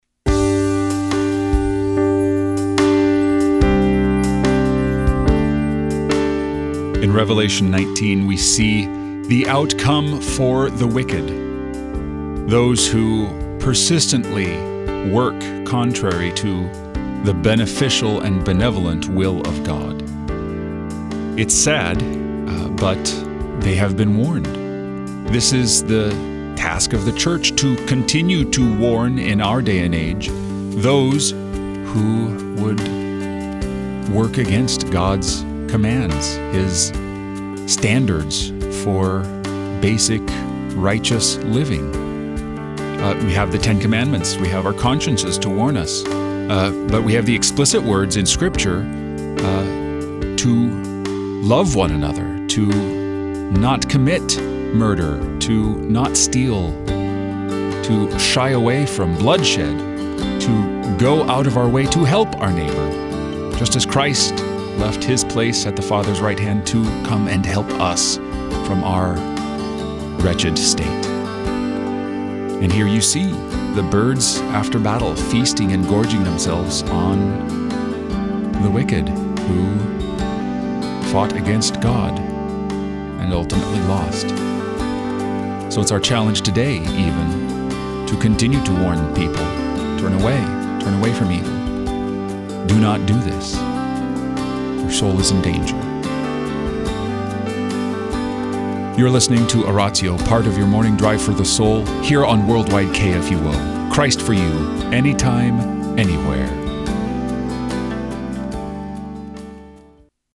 and also gives a short meditation on the day’s scripture lessons.